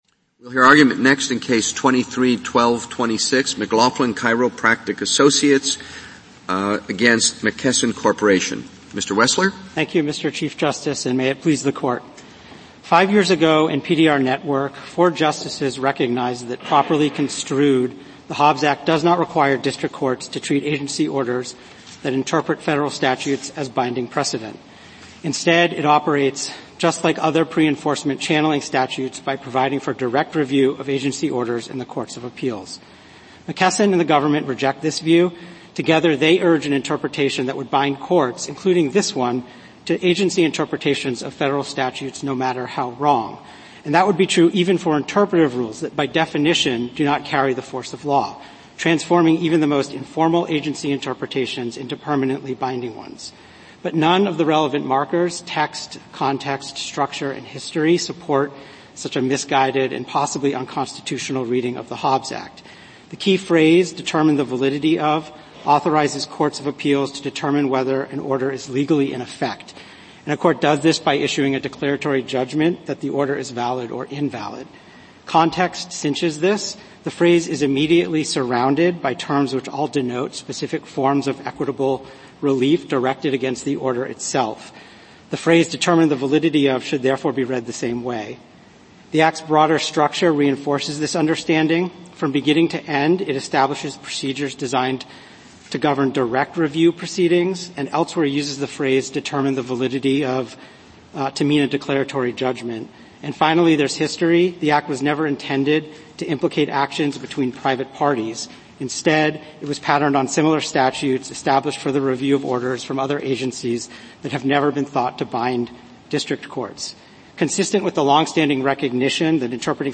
U.S. Supreme Court Oral Arguments